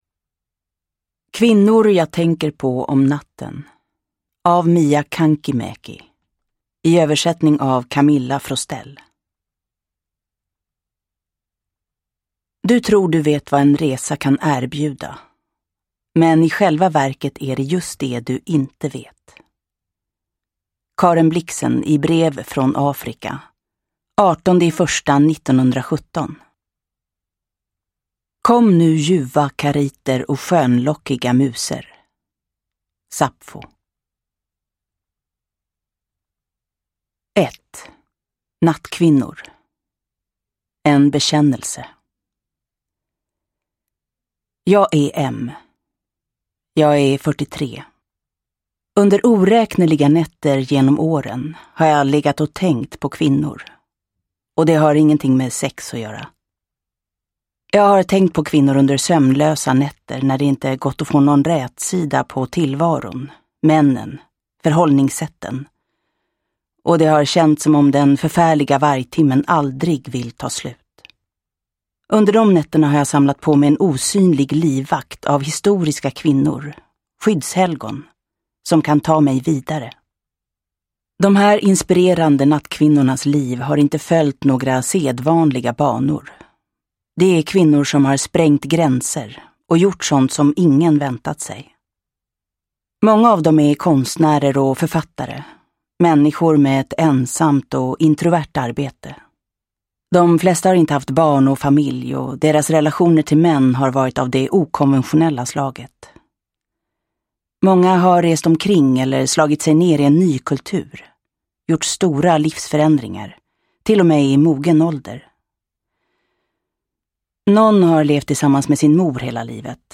Uppläsare: Jessica Liedberg